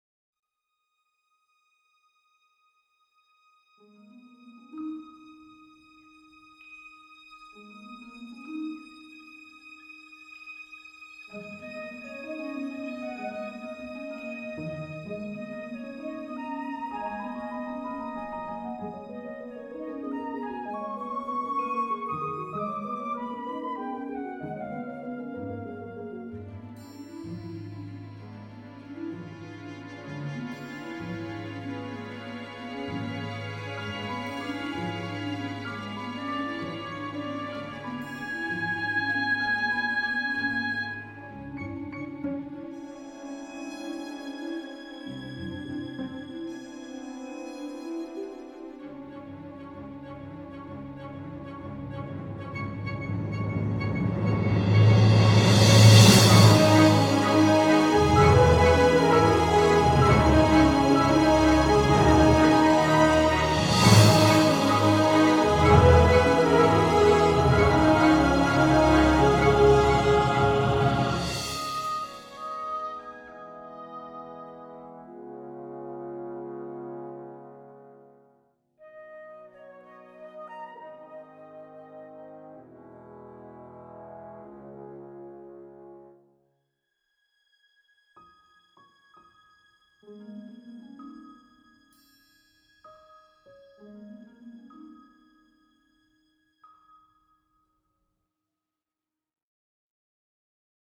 4/ La cascade des géants (bis) : La meilleure proposition pour ma part, un peu épique pour se faire plaisir mais bien maîtrise dans l'arrangement et le son. Good job !
Ce n'est pas mixé ; les Spitfire ont la délicatesse de sonner correctement sans trop les triturer.
N'ayant pas encore présenté de style dit "épique" sur ce site, j'ai trouvé ici une occasion.